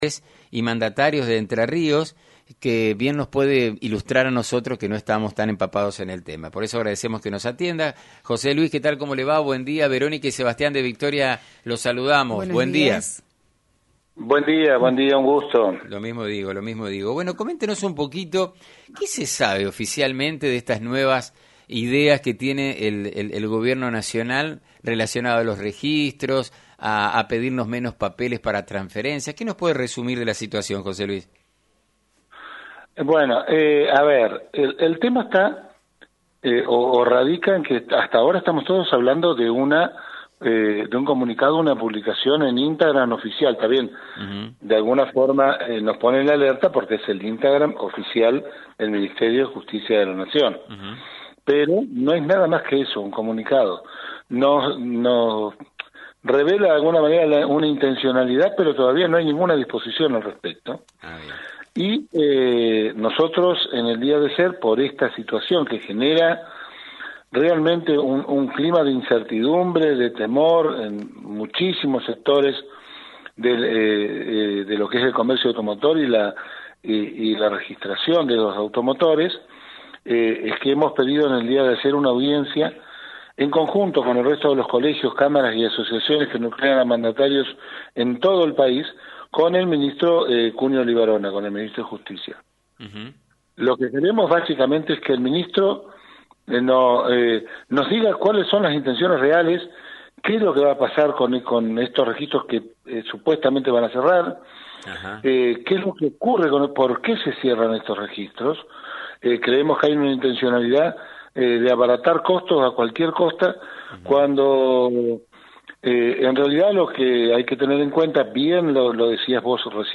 estuvo en contacto con LT39 en el programa “Burro de arranque” por FM90.3.